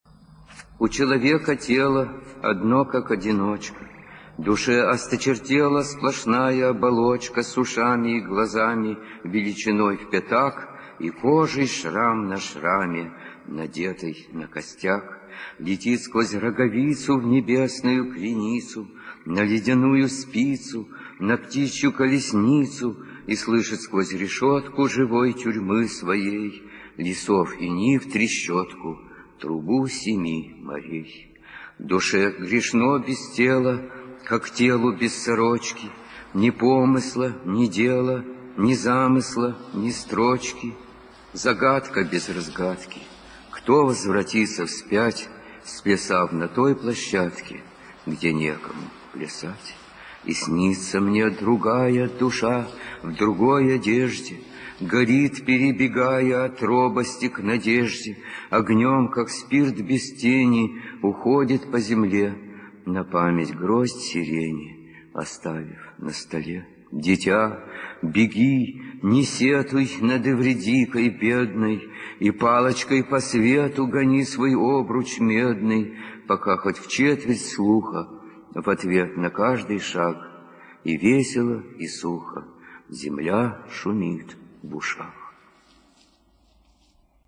9. «Арсений Тарковский – Эвридика (читает автор)» /
arsenij-tarkovskij-evridika-chitaet-avtor